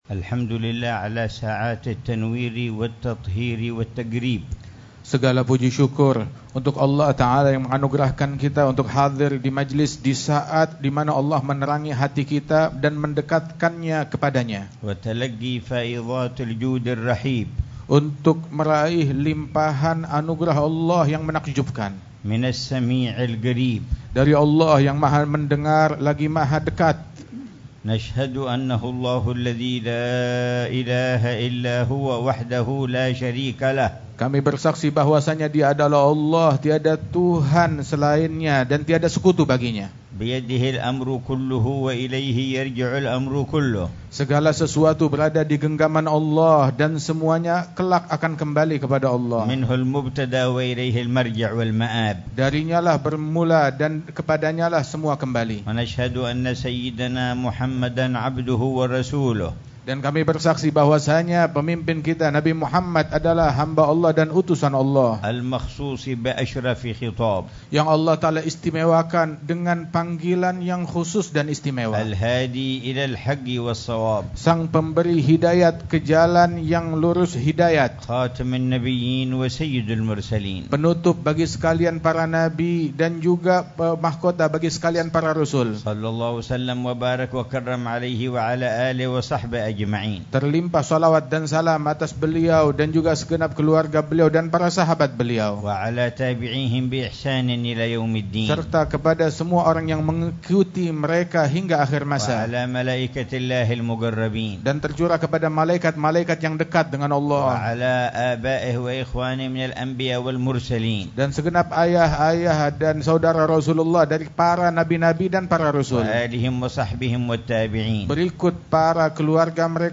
محاضرة في حولية مسجد باعلوي، سنغافورة 1447 Haul of Ba`alwie mosque, Singapore
محاضرة العلامة الحبيب عمر بن حفيظ في مجلس الذكر والتذكير في مسجد باعلوي، في سنغافورة، ليلة الجمعة 25 ربيع الثاني 1447هـ